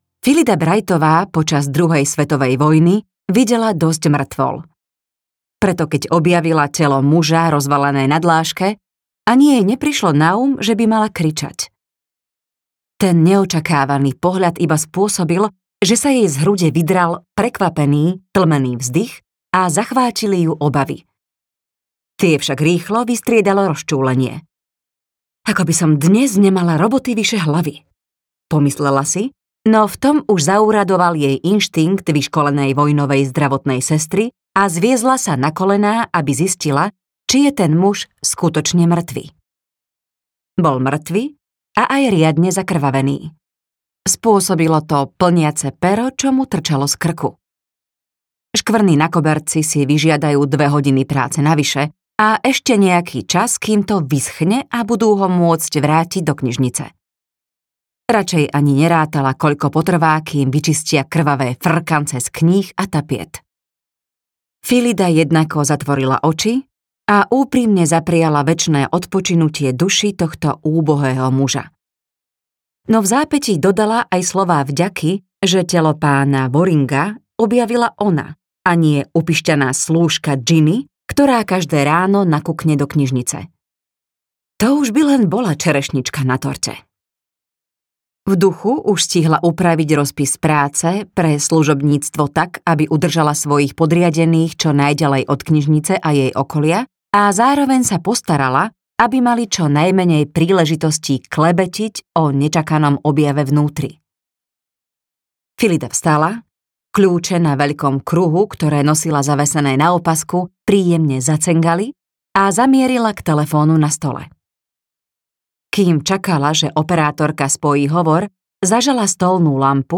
Vražda v Mallowan Hall audiokniha
Ukázka z knihy